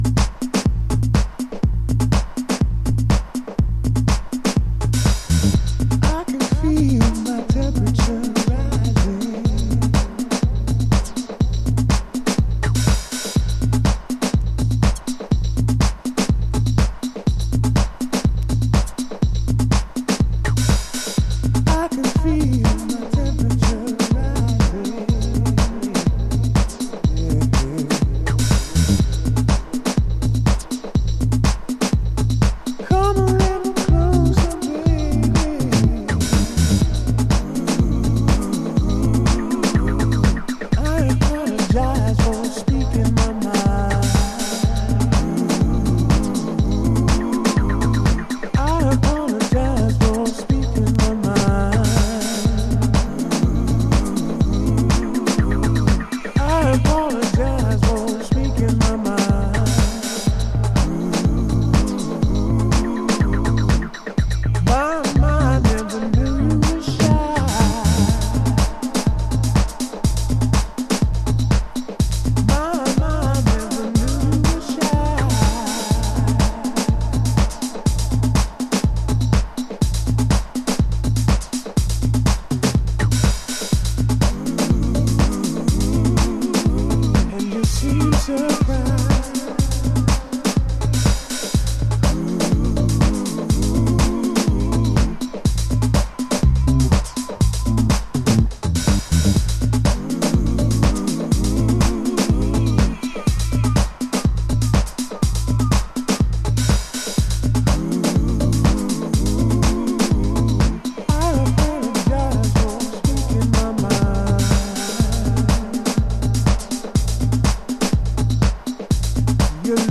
House / Techno
ディスコダブ期を経た、じわじわと引き摺り込む、ねっとりダブ処理が魅力的なアシッ度感のあるディープハウス。